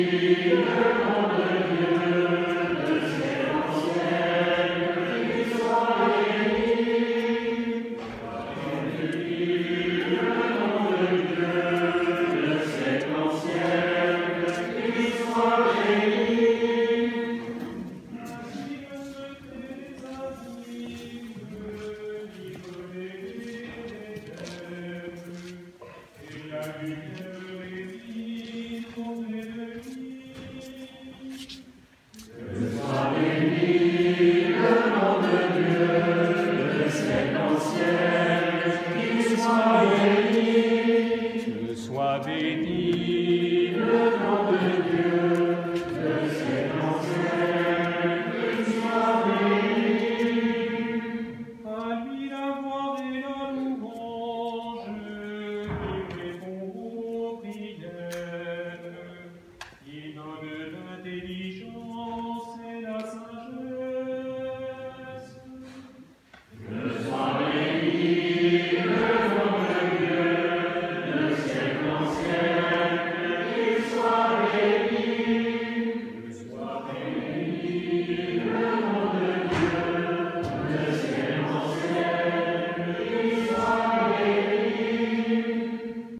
le 1er juillet 2021, à Varengeville sur mer
Célébration de la messe (1 sur 2)
JUBILÉ, fête des prêtres jubilaires
Procession d'entrée
processentree.m4a